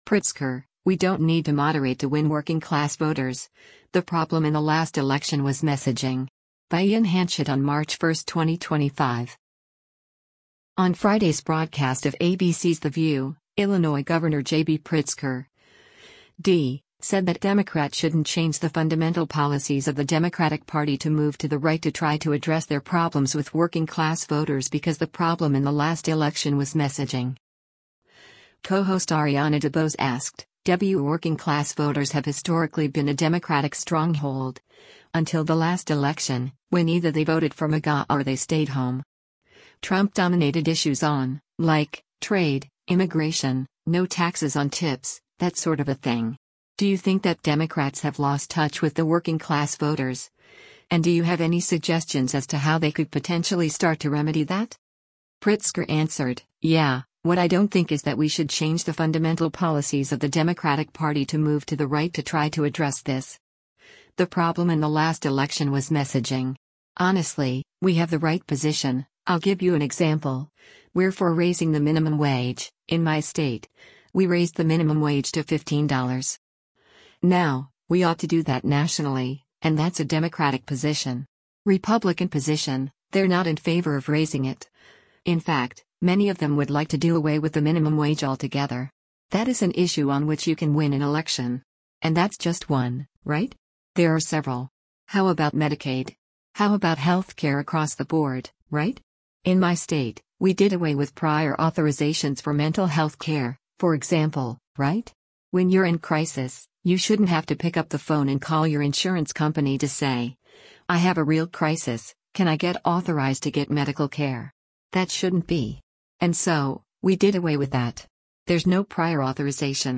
On Friday’s broadcast of ABC’s “The View,” Illinois Gov. JB Pritzker (D) said that Democrats shouldn’t “change the fundamental policies of the Democratic Party to move to the right to try to address” their problems with working-class voters because “The problem in the last election was messaging.”